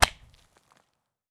Golf Hit Chip.wav